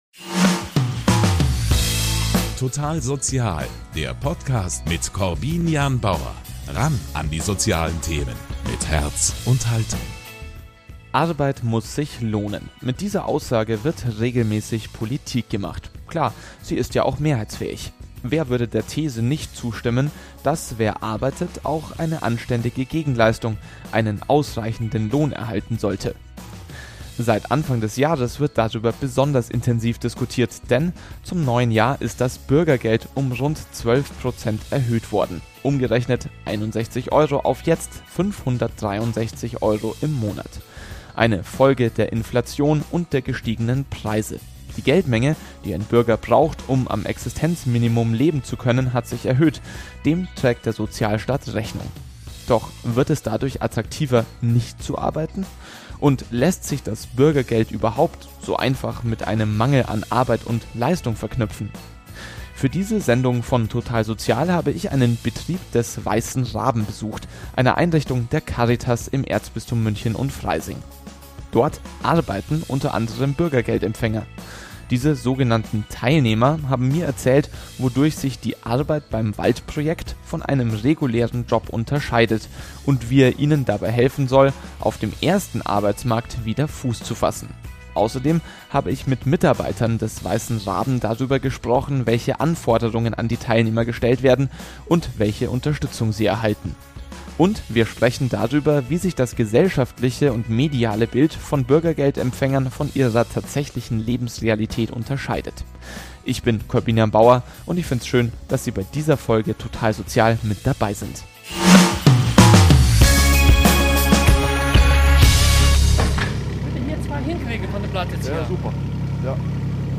Beim Waldprojekt sollen sie eine Möglichkeit bekommen, auf den regulären Arbeitsmarkt zurückzukehren. In "Total Sozial" sprechen die Teilnehmer darüber, warum sie trotz einer mageren Aufwandsentschädigung von zwei Euro pro Stunde gern beim Weißen Raben arbeiten und wie die gesellschaftliche Debatte über das Bürgergeld an der Realität vorbeigeht.